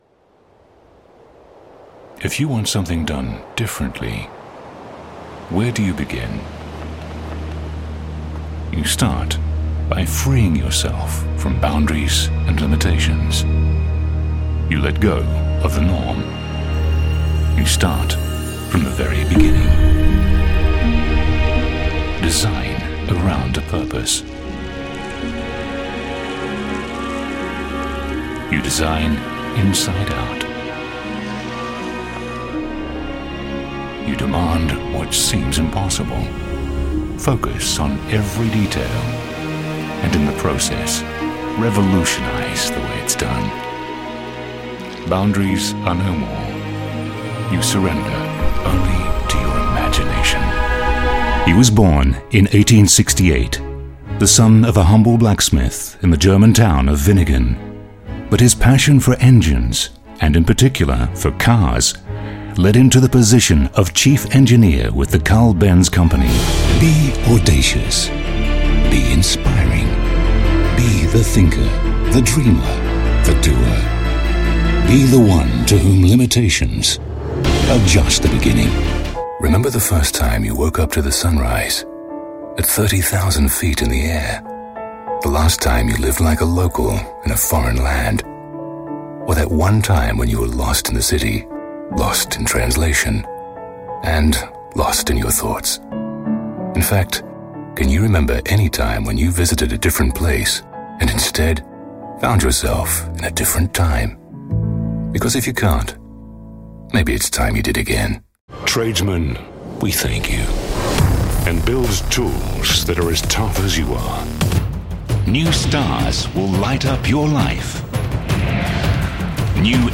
Sprecher englisch, spricht ein neutrales Englisch mit keinem erkennbaren Akkzent. Dieses Englisch wir oft als 'mid-atlantic' bezeichnet.
mid-atlantic
Sprechprobe: Werbung (Muttersprache):
Deep. Polished. Gravitas. Intimate.